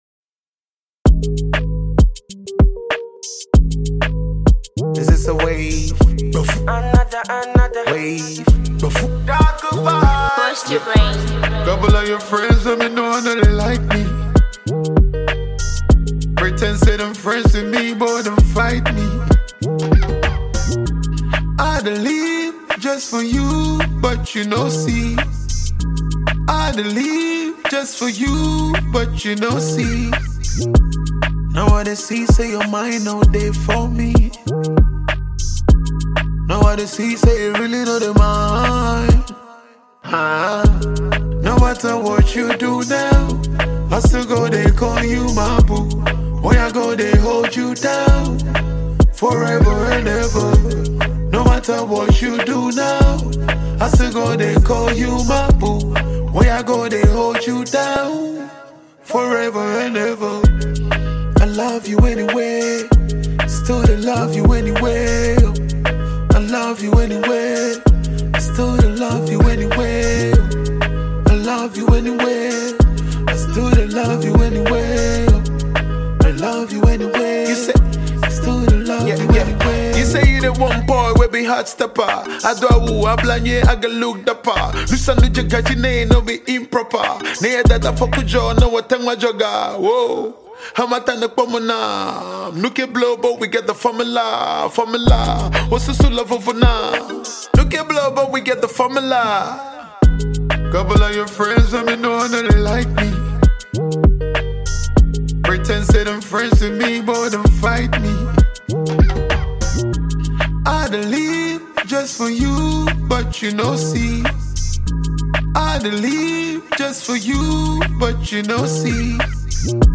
Ghana MusicMusic